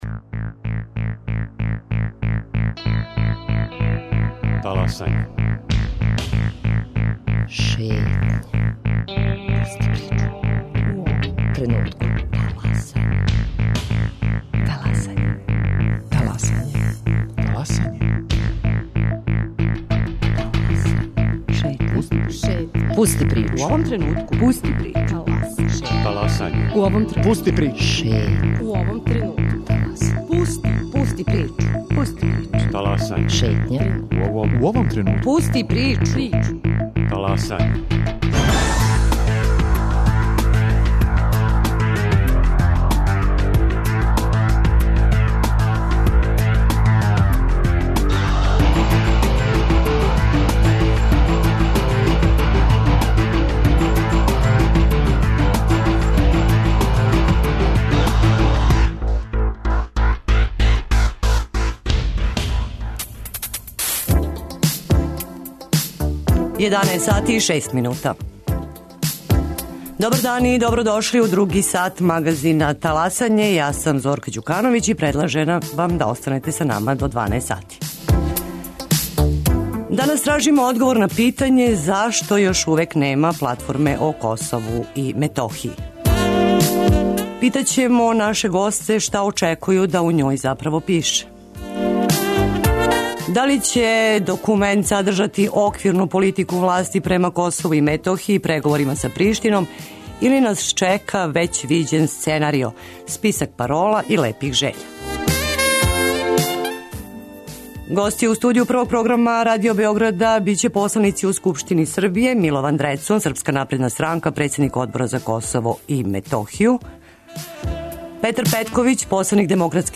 Гости, посланици у Скупштини Србије: Милован Дрецун, СНС, председник одбора за Косово и Метохију, Петар Петковић посланик ДСС-а и Бојан Ђурић посланик ЛДП-а.